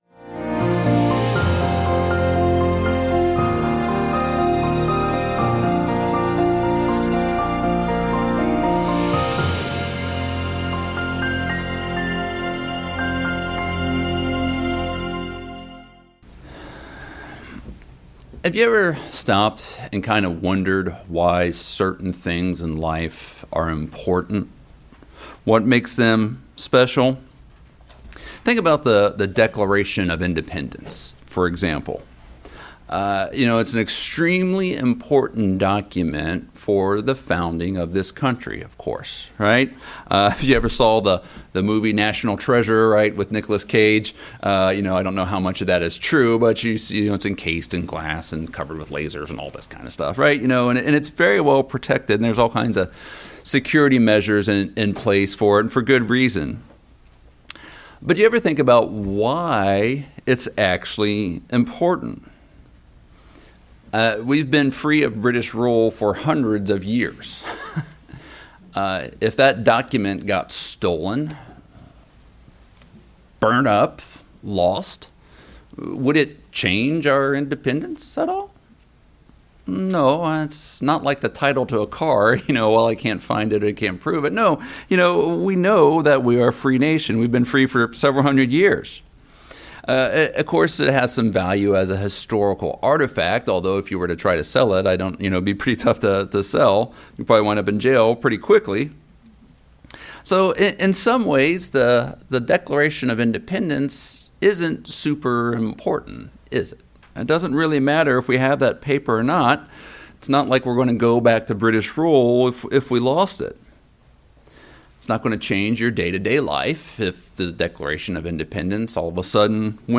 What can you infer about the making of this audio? Given in Charlotte, NC Hickory, NC Columbia, SC